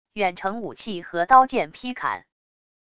远程武器和刀剑劈砍wav音频